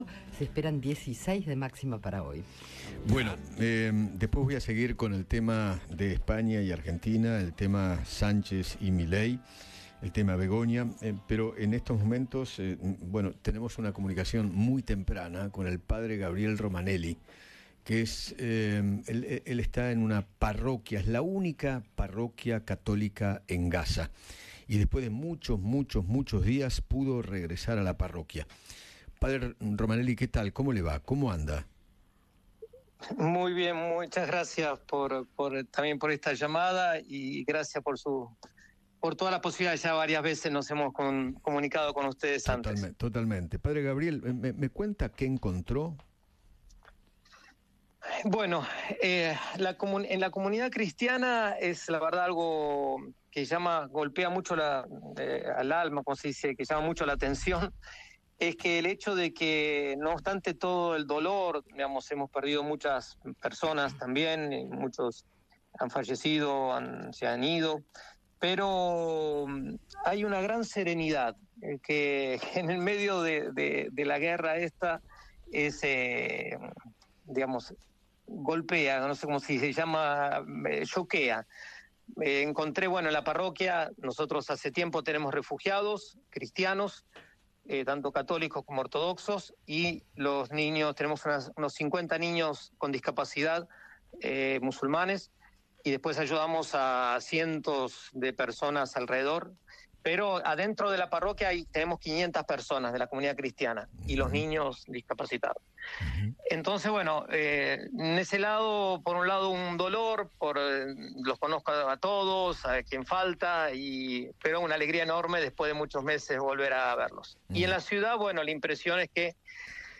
Eduardo Feinmann habló con